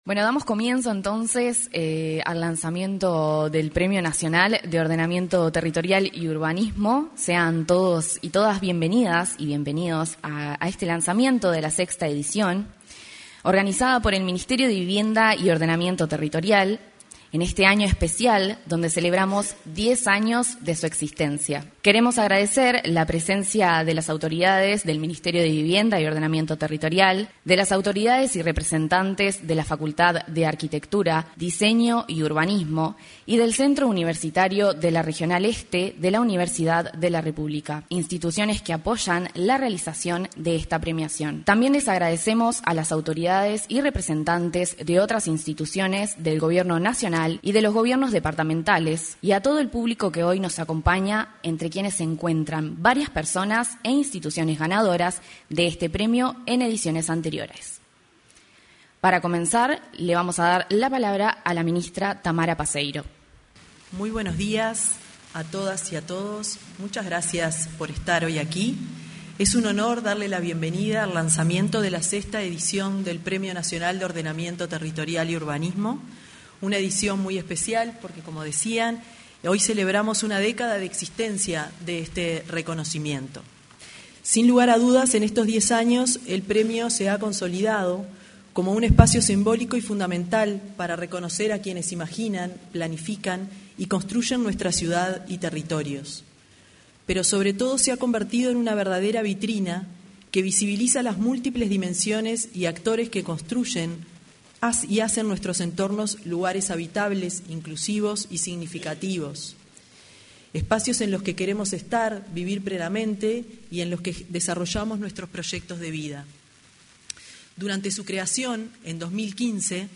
Se realizó el lanzamiento del Premio de Ordenamiento Territorial y Urbanismo 2025, en el auditorio del anexo a la Torre Ejecutiva.